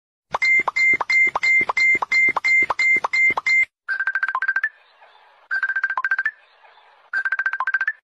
Bells money sound effects free download